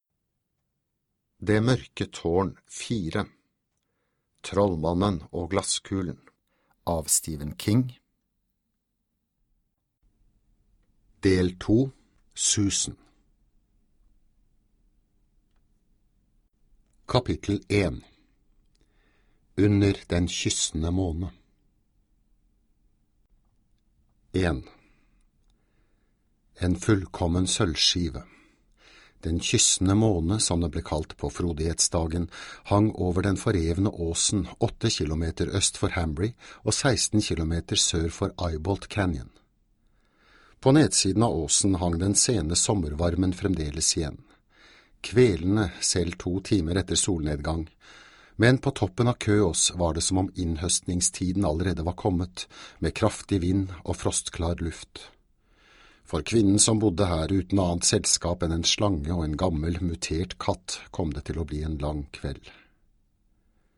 Det mørke tårn IV - Del 2 - Susan (lydbok) av Stephen King